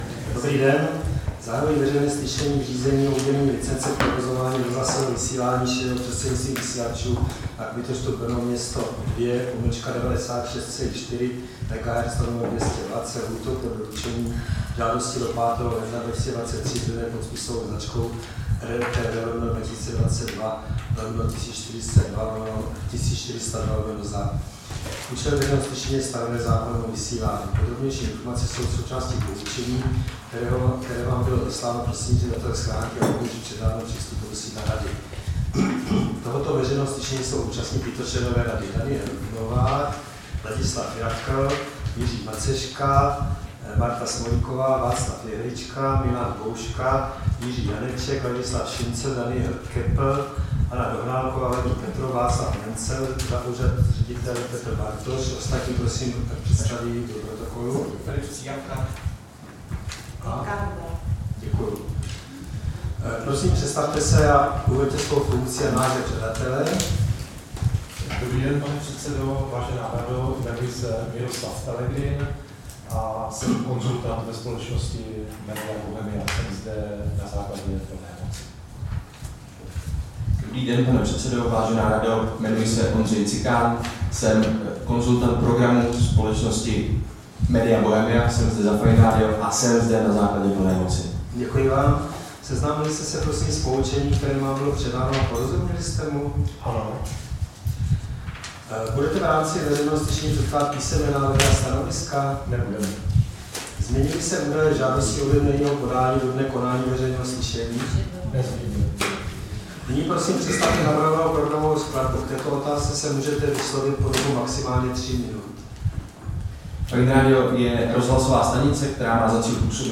Veřejné slyšení v řízení o udělení licence k provozování rozhlasového vysílání šířeného prostřednictvím vysílačů se souborem technických parametrů Brno-město 96,4 MHz/200 W
Místem konání veřejného slyšení je sídlo Rady pro rozhlasové a televizní vysílání, Škrétova 44/6, PSČ 120 00 Praha 2.